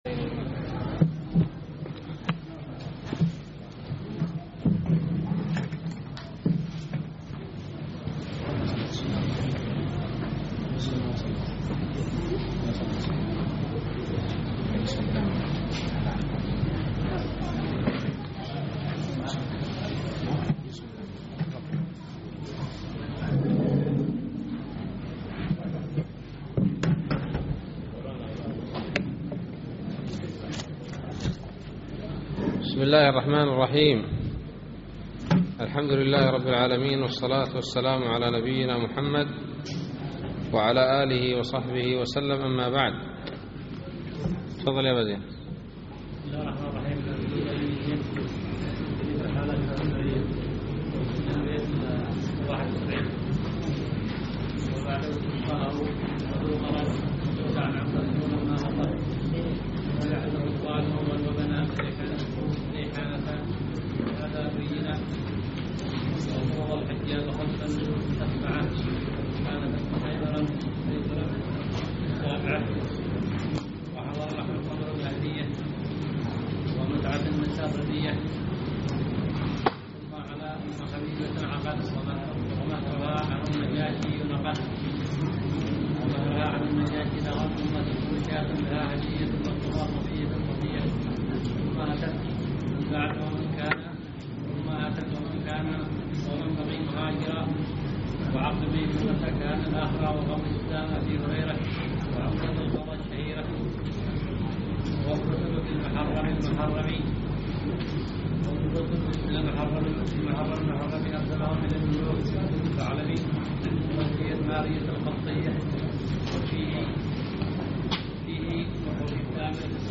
الدرس الرابع والعشرون من شرح كتاب التوحيد